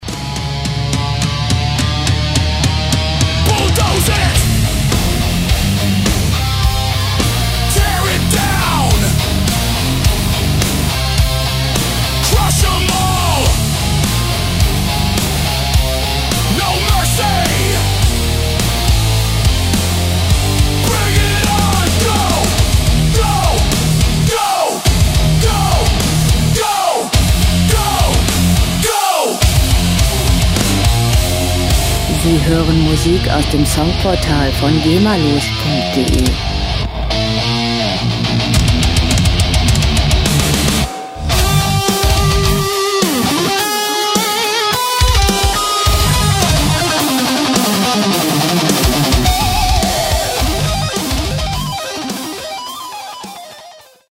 Rockmusik - Harte Männer
Musikstil: Heavy Metal
Tempo: 105,5 bpm
Tonart: G-Dur
Charakter: massiv, wuchtig
Instrumentierung: E-Gitarren, E-Bass, Drums, Synthesizer